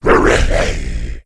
c_saurok_atk3.wav